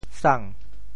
潮州 ng5 seng1 sang2 sou3 潮阳 ng5 seng1 sang2 sou3 潮州 0 1 2 3 潮阳 0 1 2 3
sang2.mp3